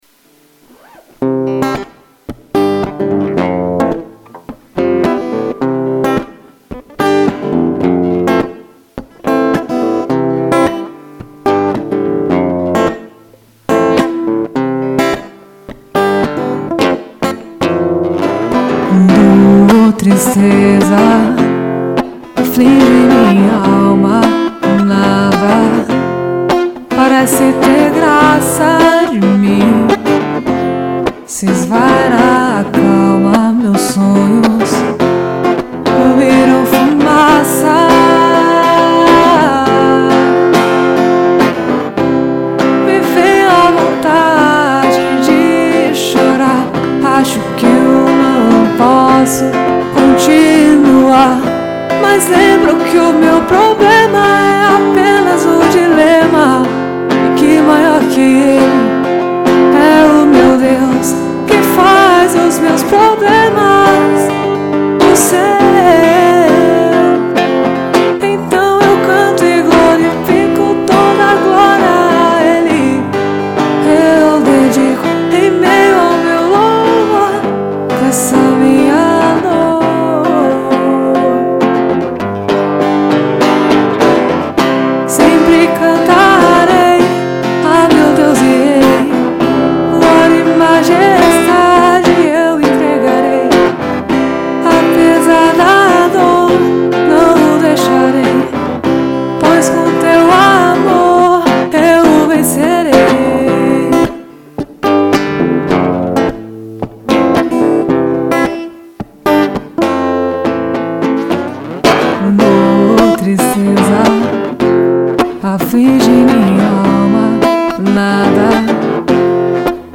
EstiloSoul